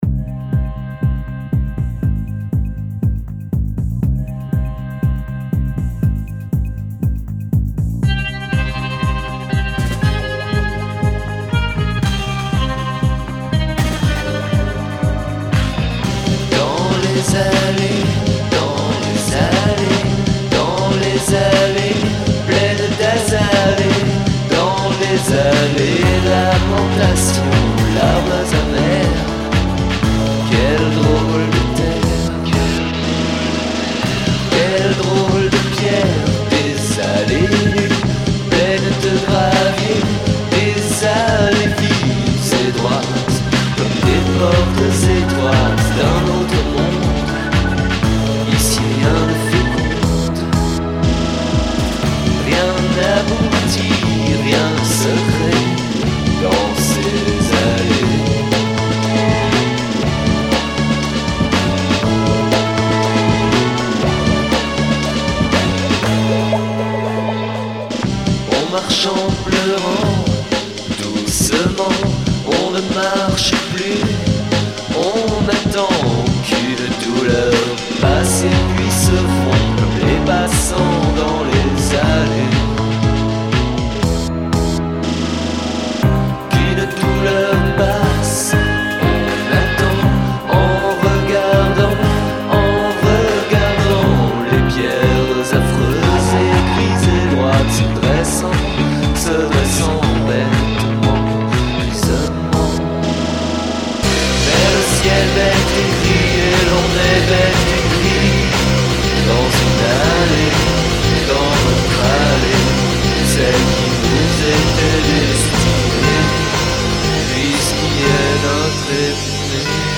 voix, guitare, piano, programmation